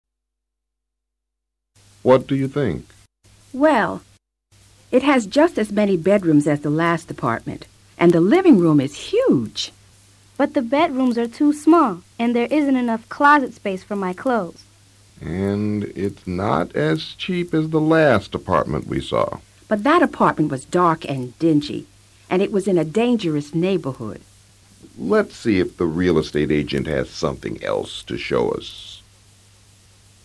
La familia Den sale a buscar un departamento y encuentran alternativas muy diferentes. Escucha con atención y trata de repetir el diálogo luego.